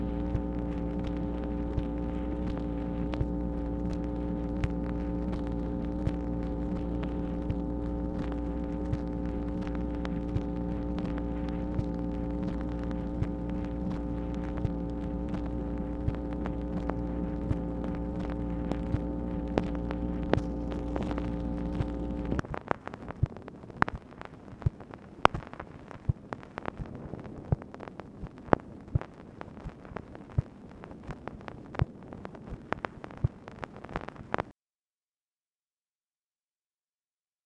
MACHINE NOISE, December 13, 1966
Secret White House Tapes | Lyndon B. Johnson Presidency